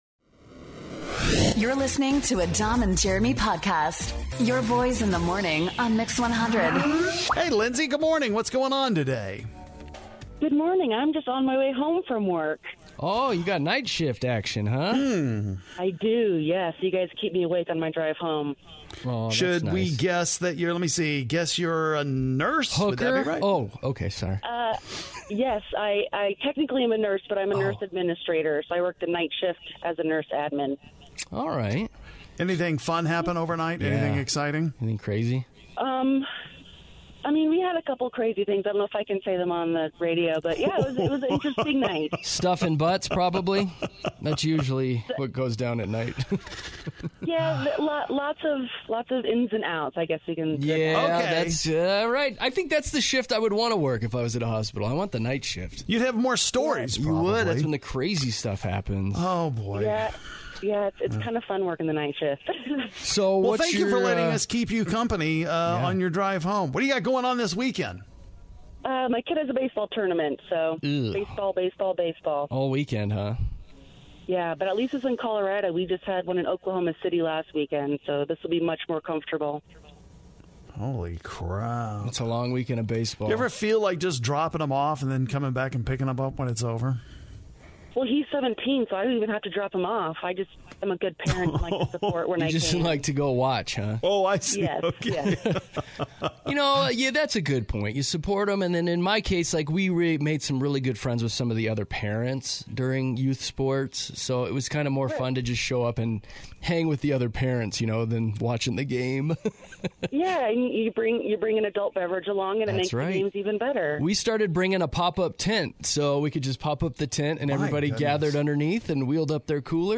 We talk to our listeners who have never called in before!